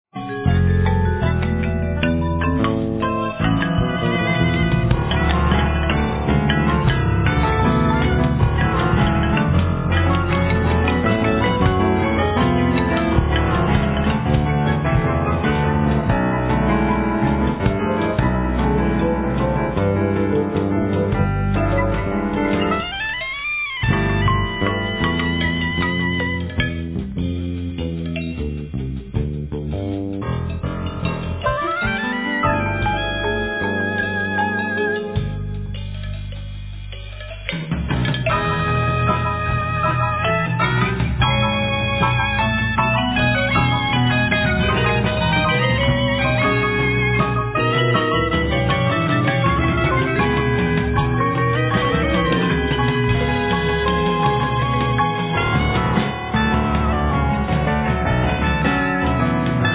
Vocals
Soprano+Tenor Sax
Yamaha Grantouch(Piano)
Marimba,Korg 01W,Programming & conducting
5-string e.bass
Drums